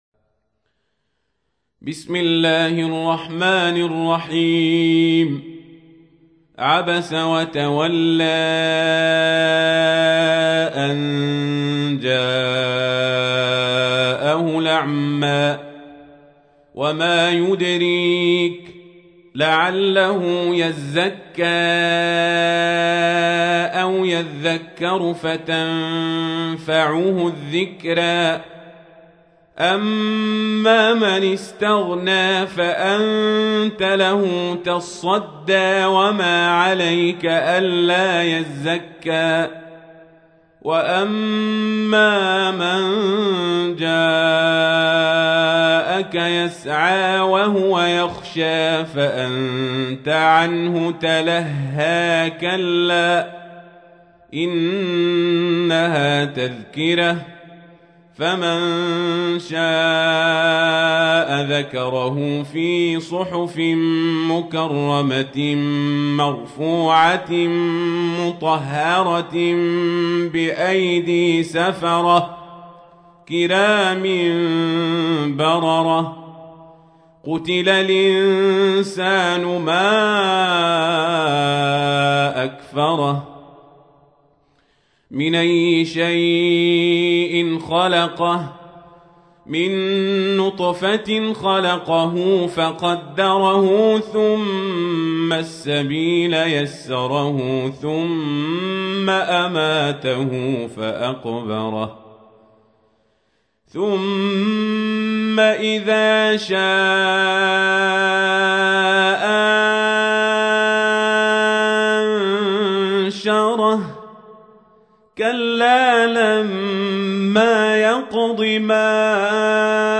تحميل : 80. سورة عبس / القارئ القزابري / القرآن الكريم / موقع يا حسين